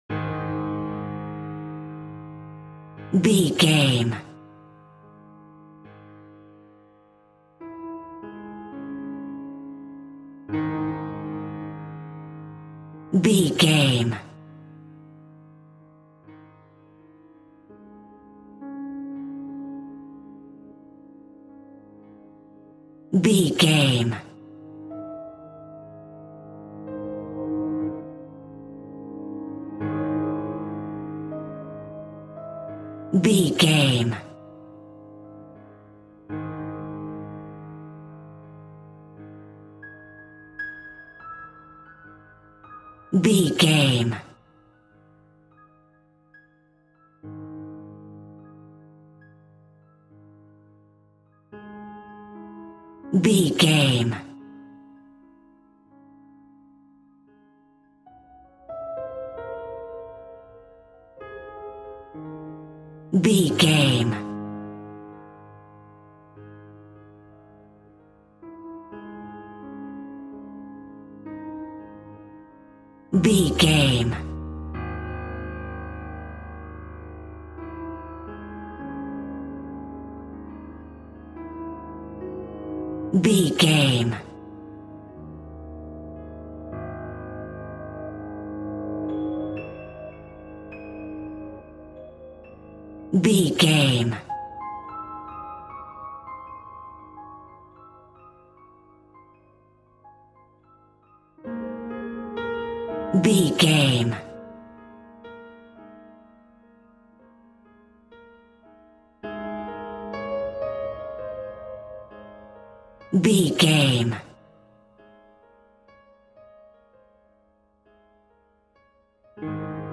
Ambient Death Scene.
Aeolian/Minor
C#
Slow
scary
tension
ominous
dark
haunting
eerie
piano
synth
ambience
pads